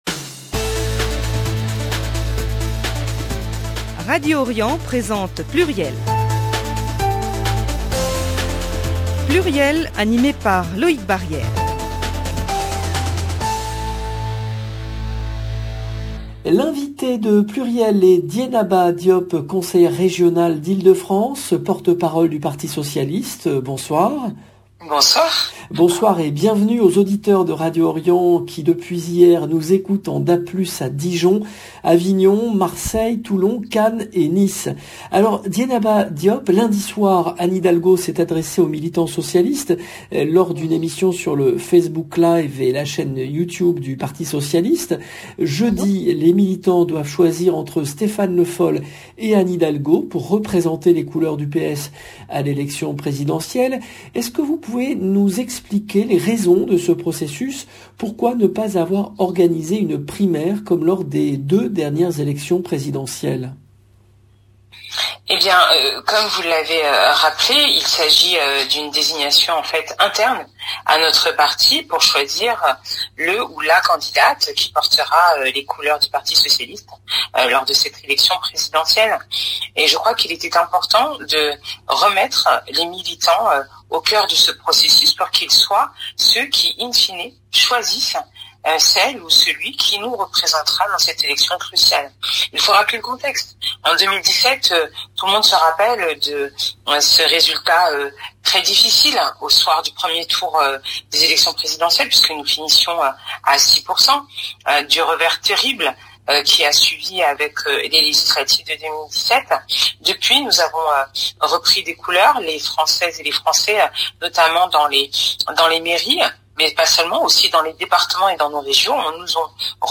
Dieynaba Diop, porte-parole du parti socialiste, invitée de PLURIEL
Emission diffusée le mercredi 13 octobre 2021